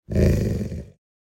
Minecraft Version Minecraft Version latest Latest Release | Latest Snapshot latest / assets / minecraft / sounds / mob / wolf / big / growl2.ogg Compare With Compare With Latest Release | Latest Snapshot
growl2.ogg